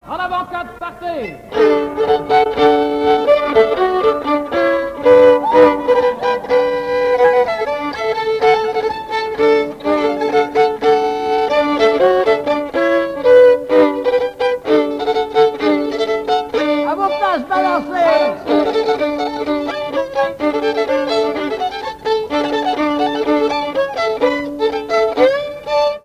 danse : quadrille : avant-quatre
Pièce musicale inédite